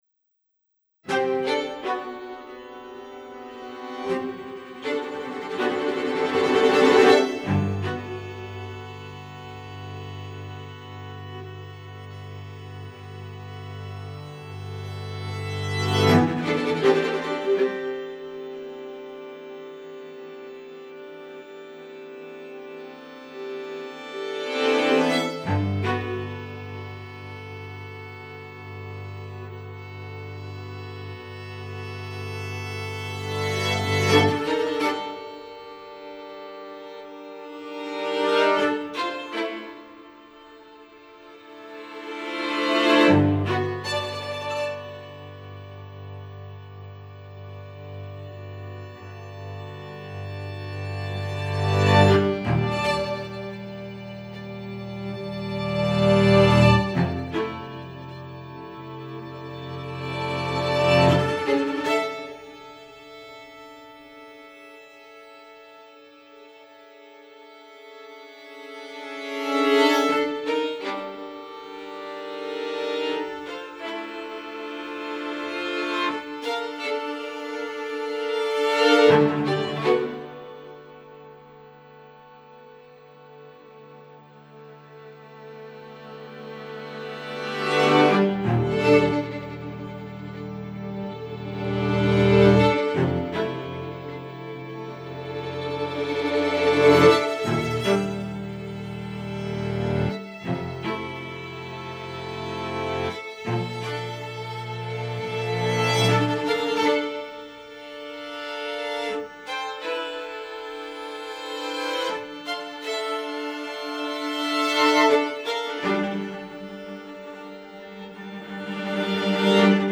Se grabó en la Ciudad de México en el 2021.
It was recorded in 2021 in Mexico City.